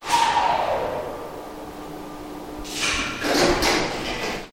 ZeroVR / FmodProject / Assets / Doors / SteamDoor / door_start.wav
door_start.wav